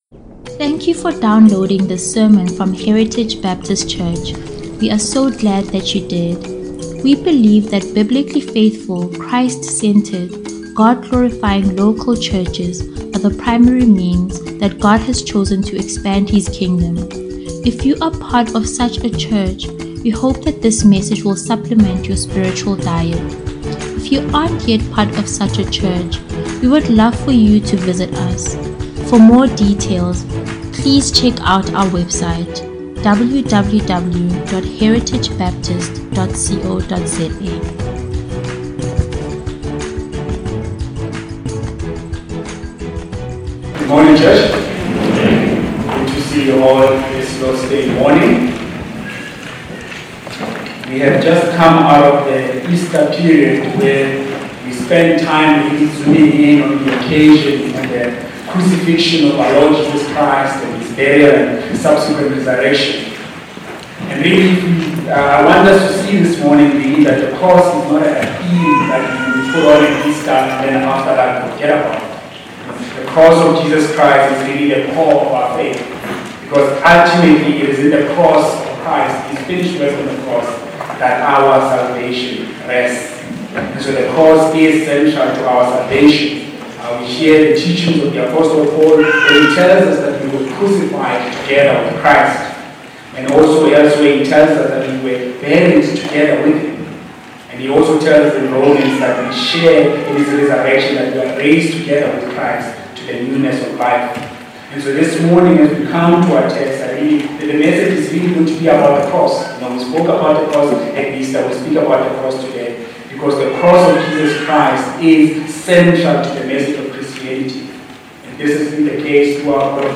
Heritage Sunday Sermons